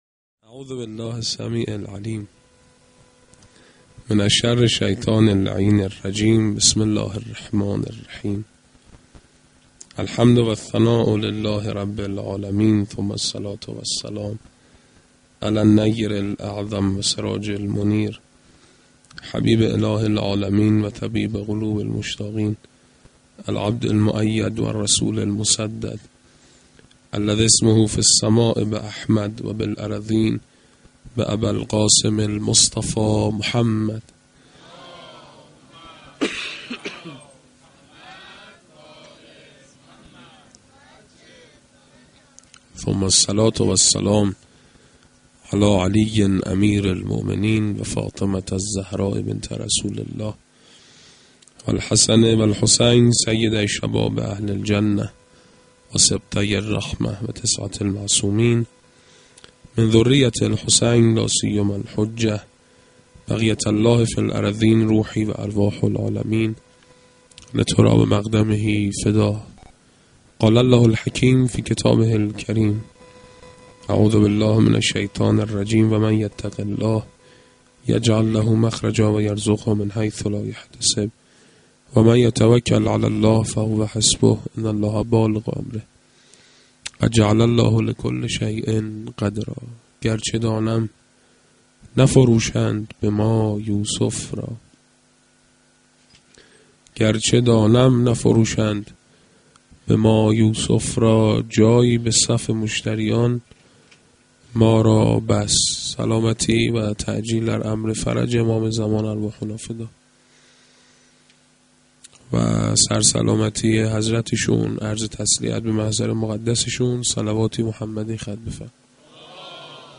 sa92-sh3-Sokhanrani.mp3